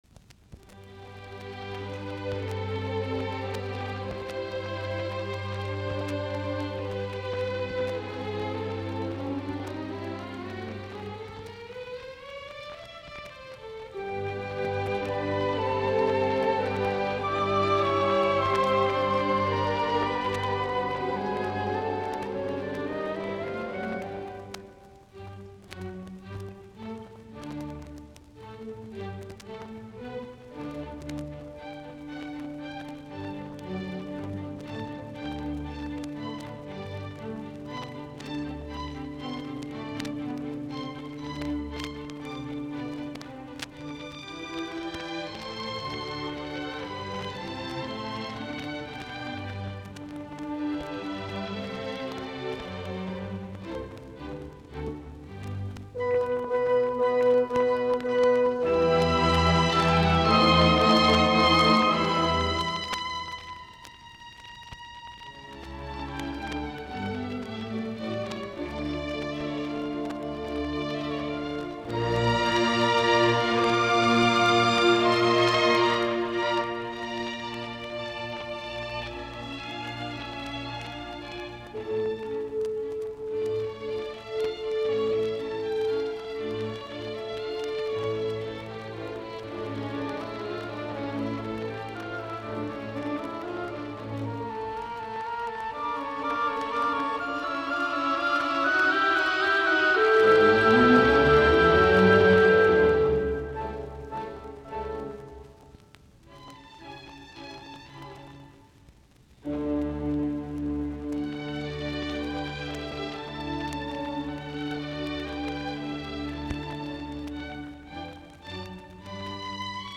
musiikkiäänite
Soitinnus: Ork.
Äänitetty: kaudella 1954-55, Chicago, Orchestra Hall.